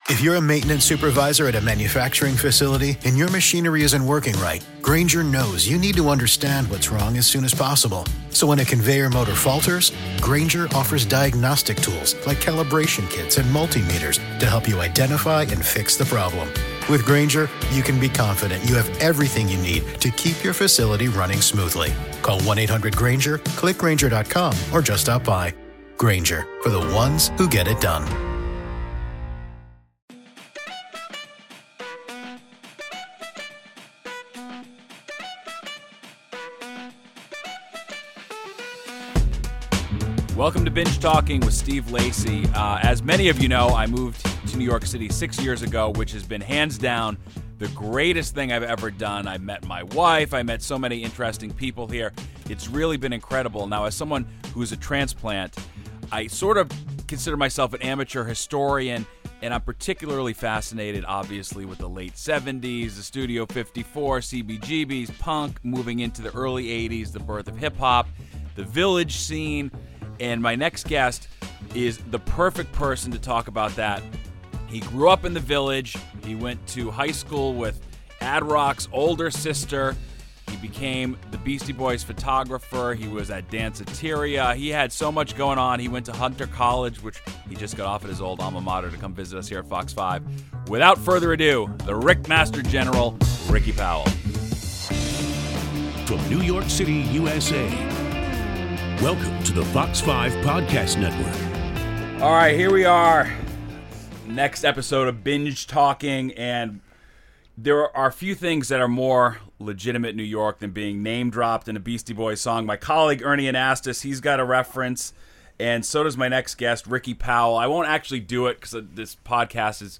Homeboy don't throw in the towel, episode 2 of 'Binge Talking' is a sit-down with Ricky Powell. A legendary Village character, Ricky has been documenting city life since the early 80's. His images of the Beastie Boys, Run DMC, LL Cool J and countless others helped spread the g...